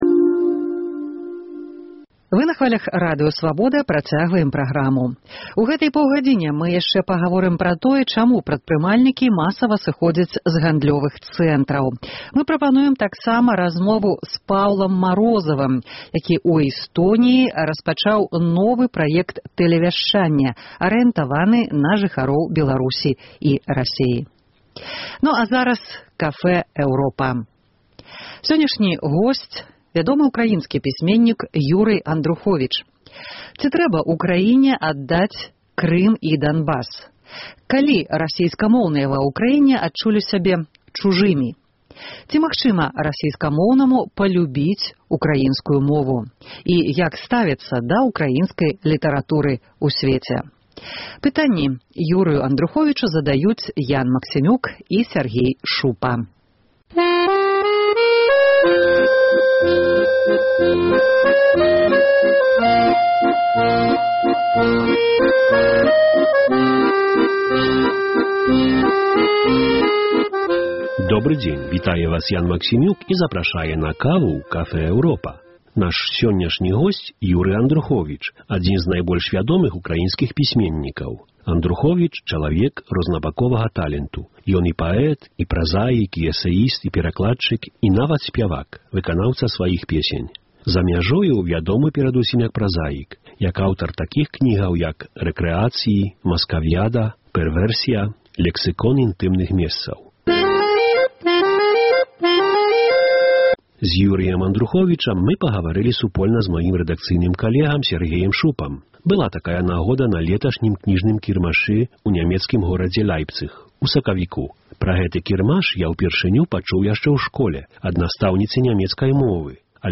Сёньняшні госьць Cafe Europa – вядомы украінскі пісьменьнік Юры Андруховіч. Ці Ўкраіне трэба аддаць Крым і Данбас? Калі расейскамоўныя ва Ўкраіне адчулі сябе адчужанымі?